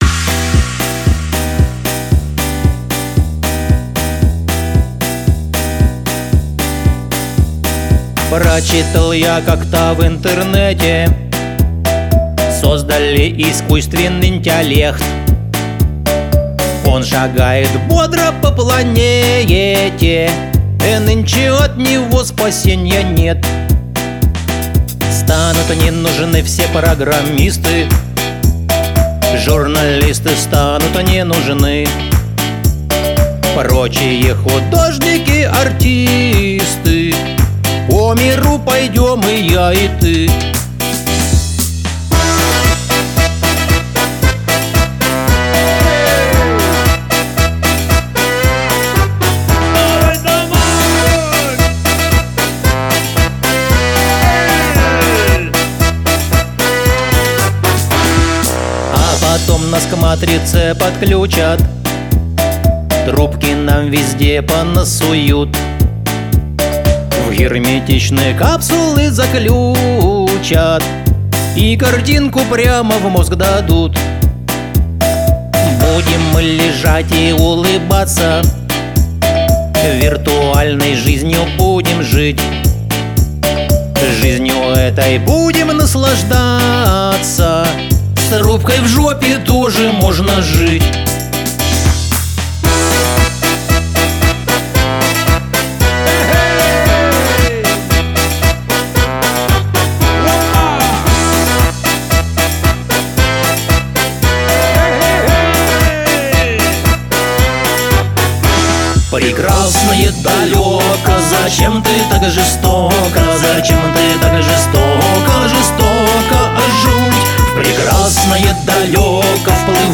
Сыграно-спето самобытно и с чувством.